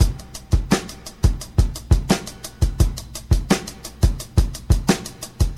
• 86 Bpm Vinyl Record Soul Breakbeat Sample F# Key.wav
Free drum beat - kick tuned to the F# note.
86-bpm-vinyl-record-soul-breakbeat-sample-f-sharp-key-H46.wav